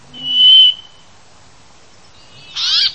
Fringilla montifringilla
Richiami ‘ìììp’, ‘toc’ e ‘ciuk’. Canto un monotono e protratto ‘uìììììì’.
Peppola_Fringilla_montifringilla.mp3